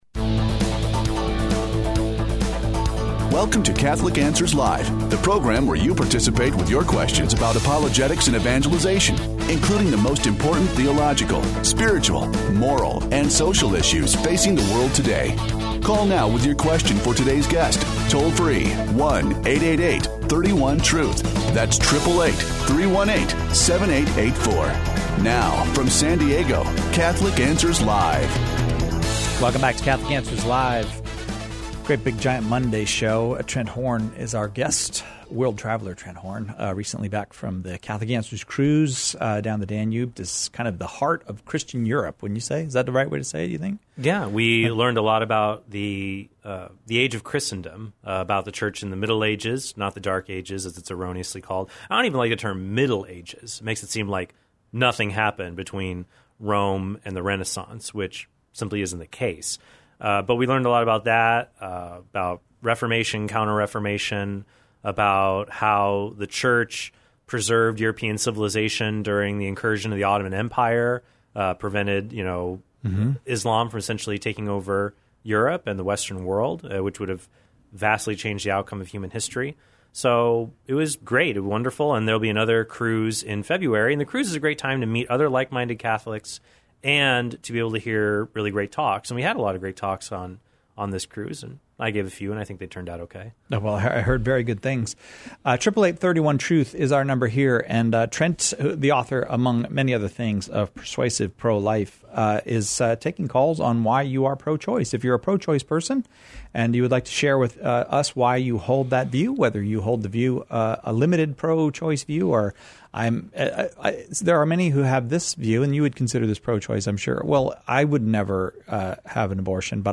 invites pro-choice listeners to explain why they are pro-choice.